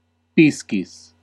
Ääntäminen
Ääntäminen Classical: IPA: /ˈpis.kis/ Haettu sana löytyi näillä lähdekielillä: latina Käännös Ääninäyte Substantiivit 1. fish UK US Suku: m .